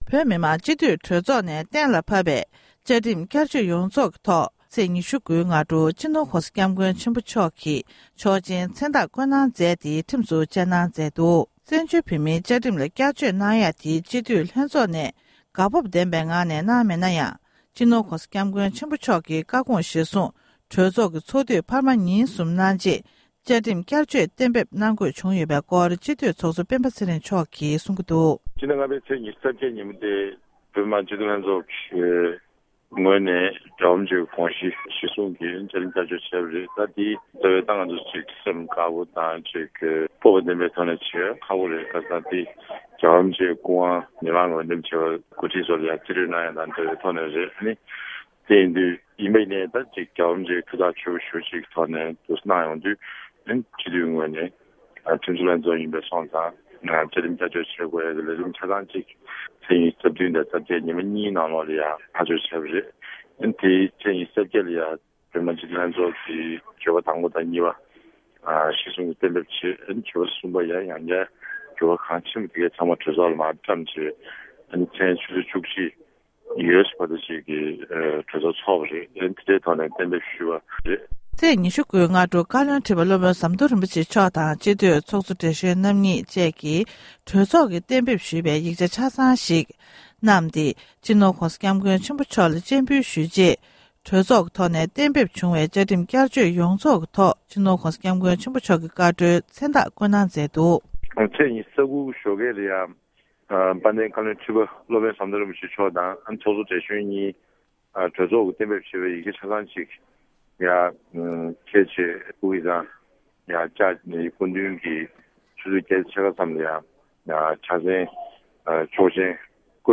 སྒྲ་ལྡན་གསར་འགྱུར།
གནས་འདྲི་ཞུས་པ༎